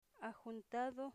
Phonological Representation axun'tado